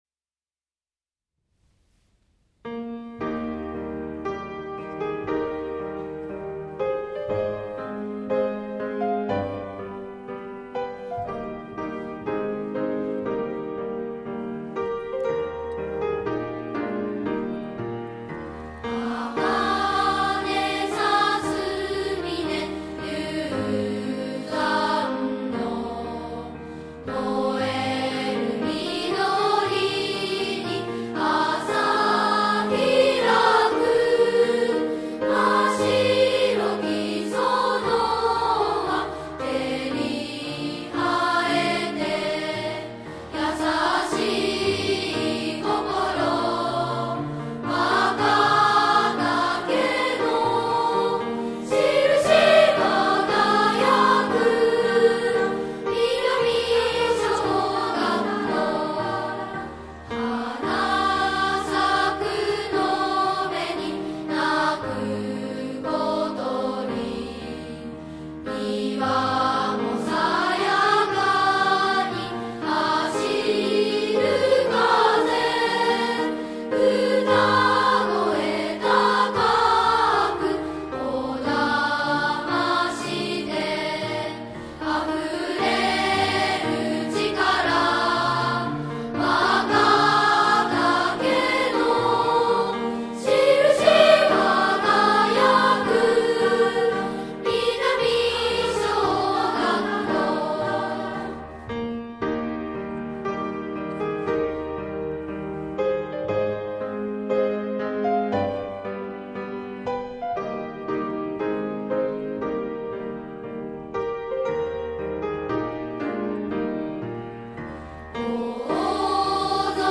１、校歌